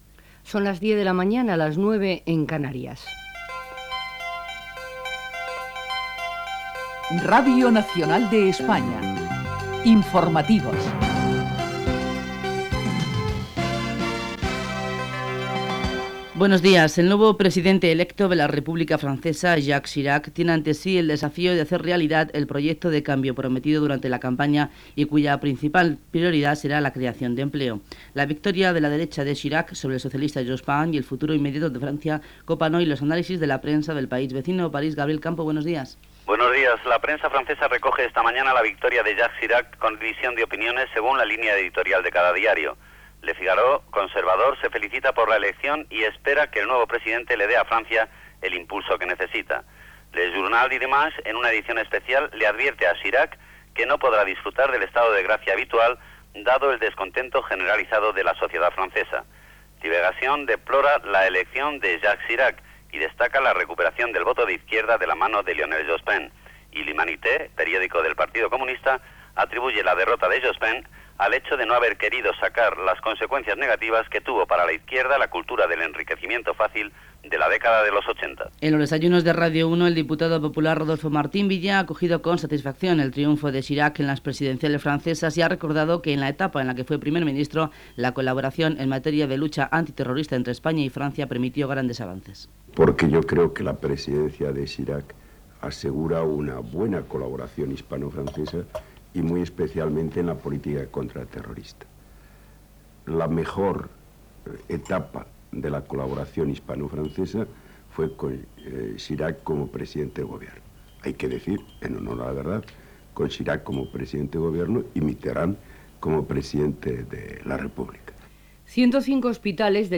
Hora, careta, nou president a França, vaga a l'INSALUD, premis Sant Jordi de cinematografia de RNE, estat del temps
Informatiu